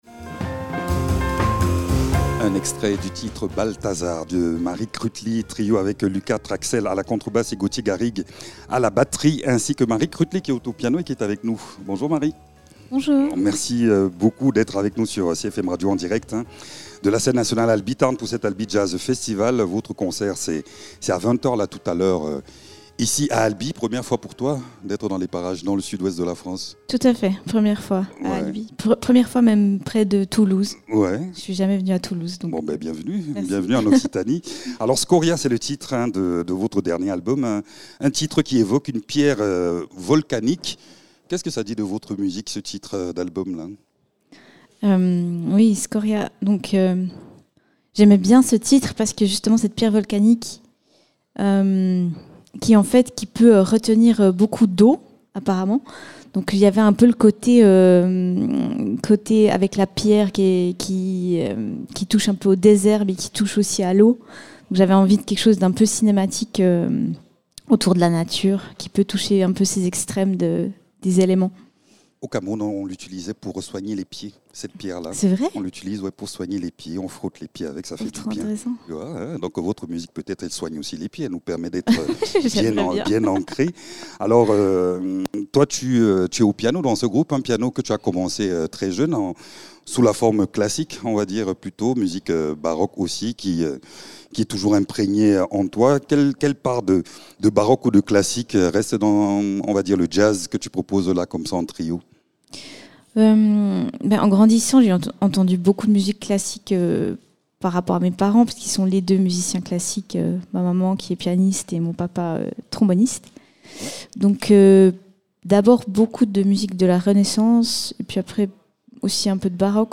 Une musique inspirante et audacieuse, simplement pleine de vie.
piano et composition.